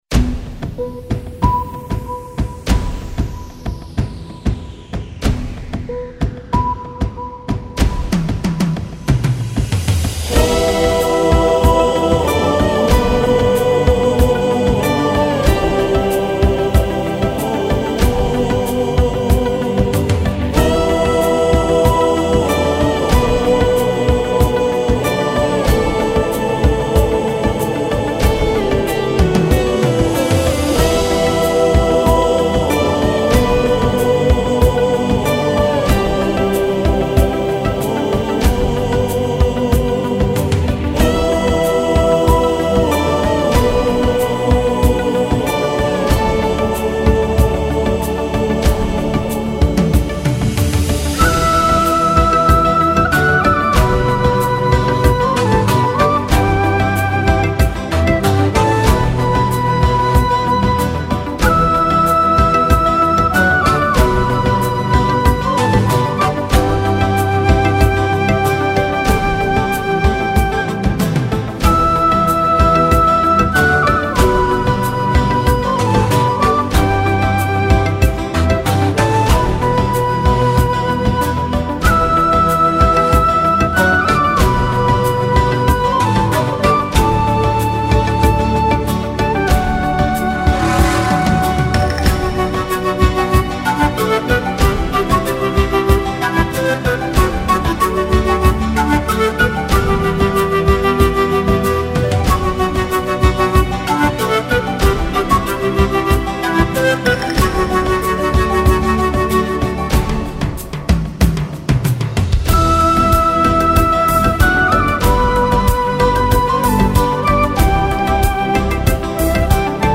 Native American wind instruments fused with electronic music